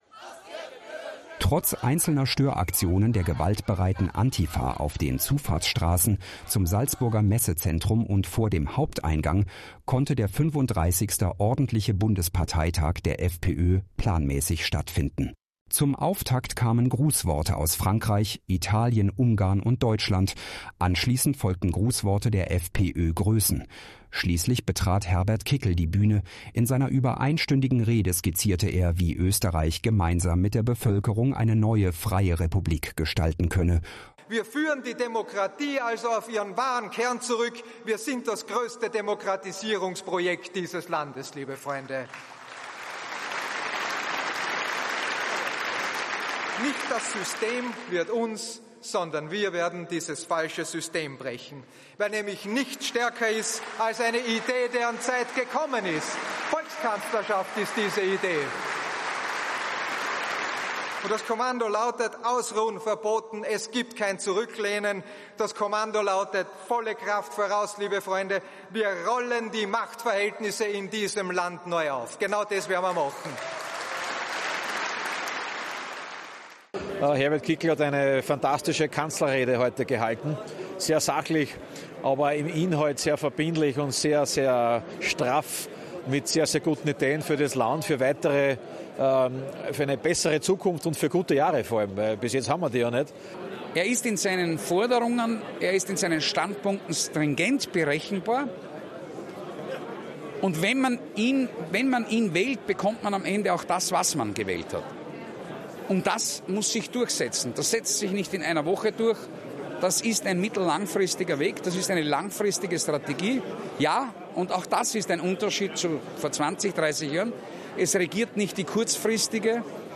Beim FPÖ-Bundesparteitag in Salzburg wurde Herbert Kickl am